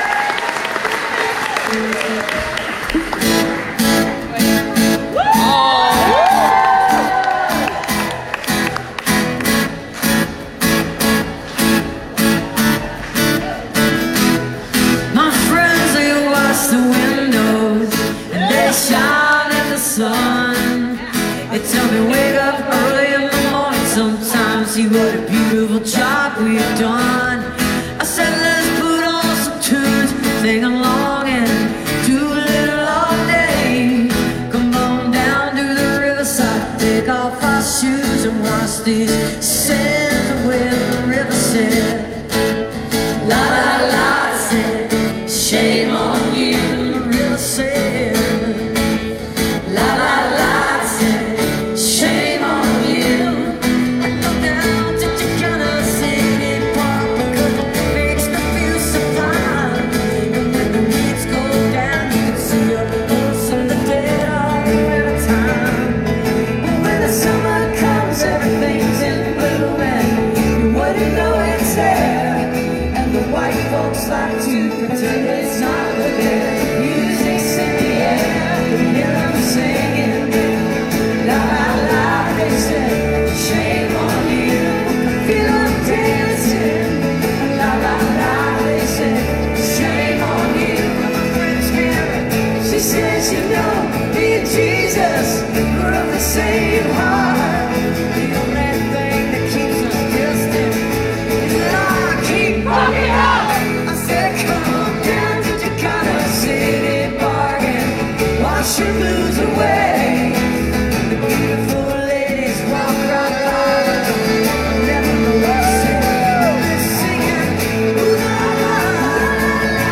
(captured from facebook live streams)